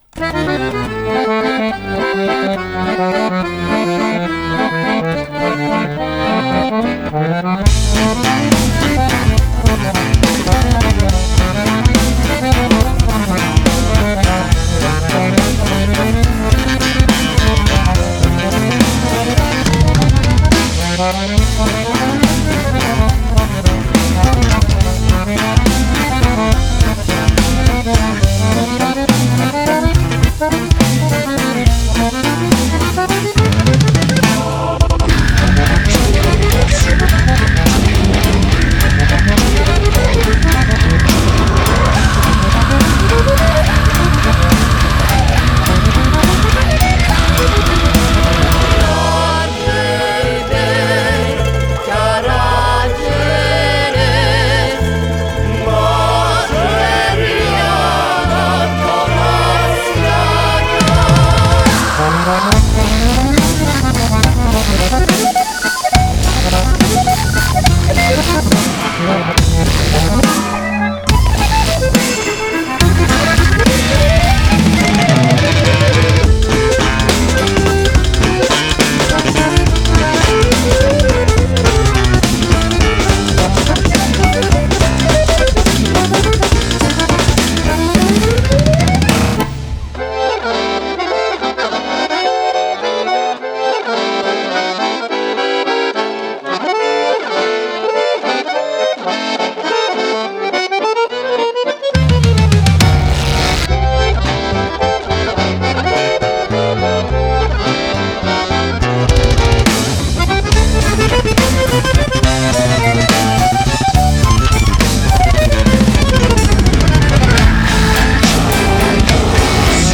BPM70-210
Audio QualityMusic Cut
Genre: Industrial Death Waltz